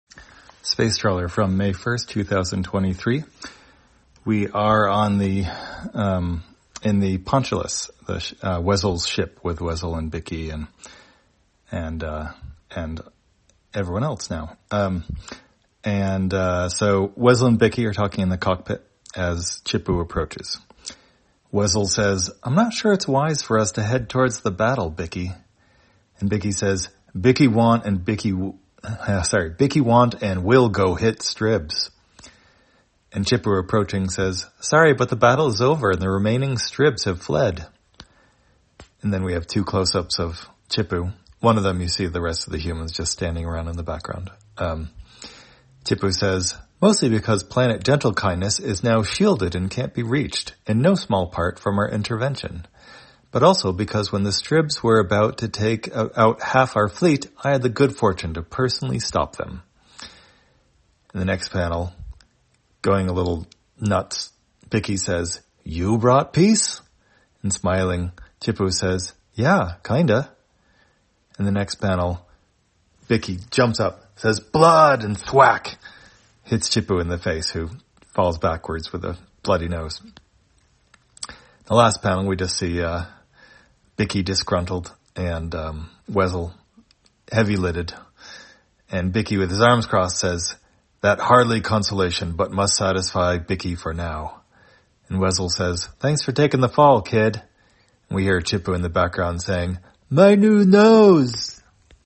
Spacetrawler, audio version For the blind or visually impaired, May 1, 2023.